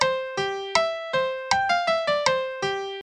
Melodiediktat B 2/4 Takt, C-Dur, Anfangston g‘